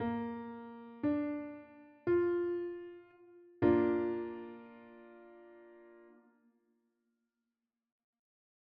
The audio examples in this course will always play triads both melodically and harmonically.
Bb-Major-Triad-S1.wav